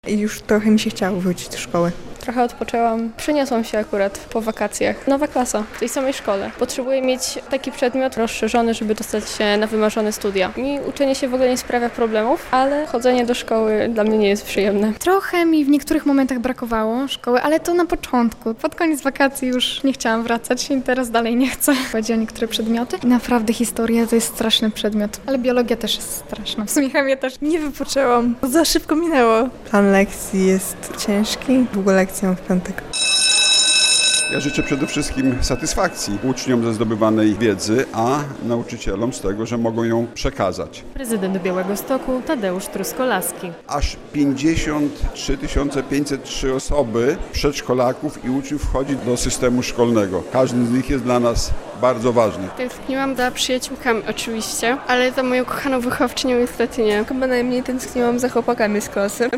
Miejskie rozpoczęcie nowego roku szkolnego w Białymstoku - relacja